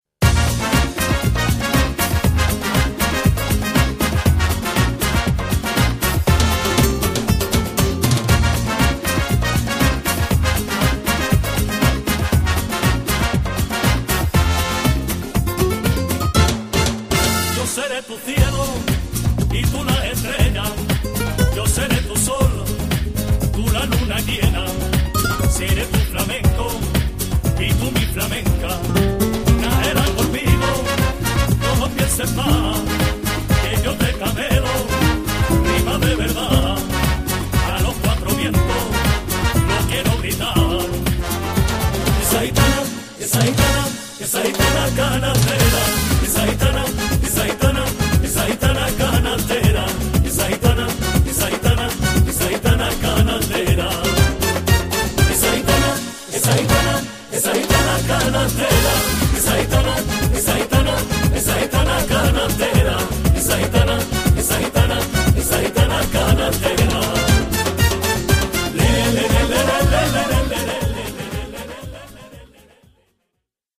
最初から最後まで、彼らのルーツ音楽である『ルンバ・フラメンコ』に対する敬意・愛が溢れている！